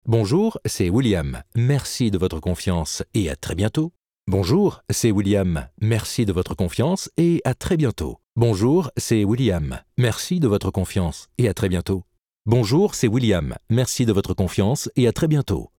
Catalogue de voix
Voix Homme